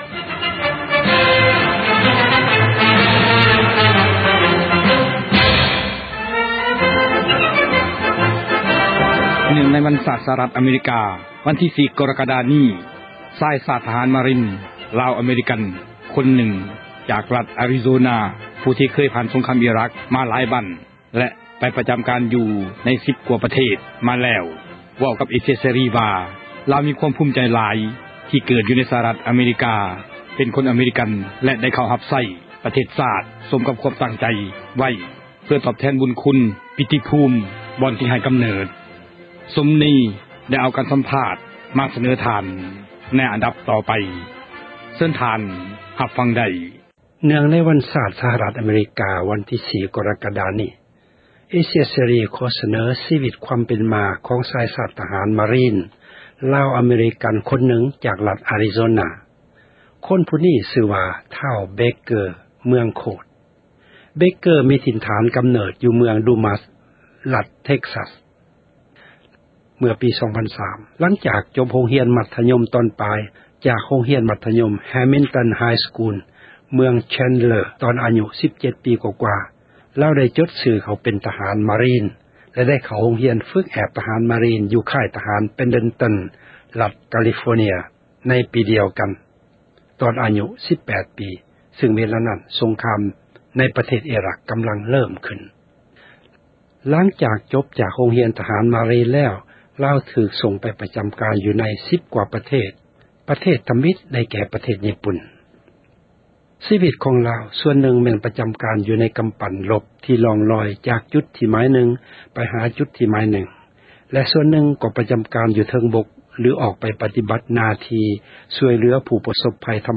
ສັມພາດ